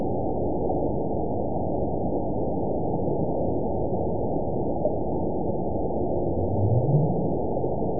event 912228 date 03/21/22 time 19:07:18 GMT (3 years, 1 month ago) score 9.62 location TSS-AB02 detected by nrw target species NRW annotations +NRW Spectrogram: Frequency (kHz) vs. Time (s) audio not available .wav